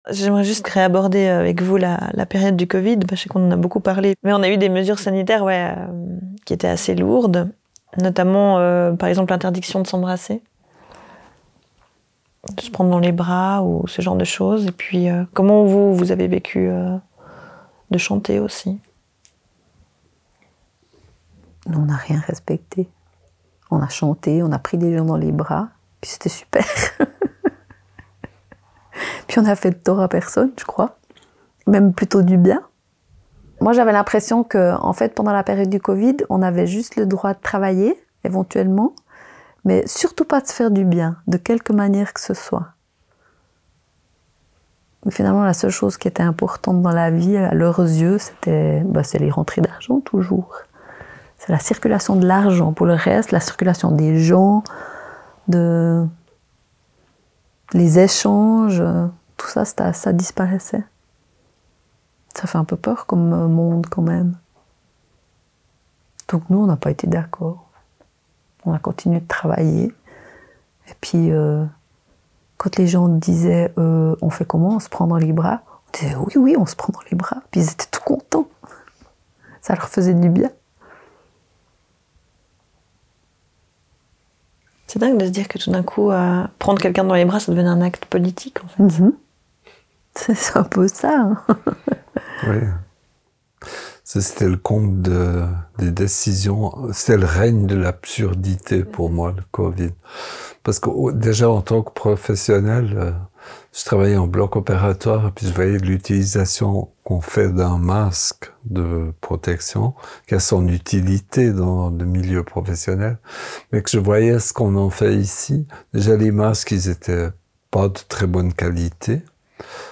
Un couple partage son point de vue sur la période du Covid, où l’humour et les petits actes de résistance se rejoignent pour critiquer le système capitaliste.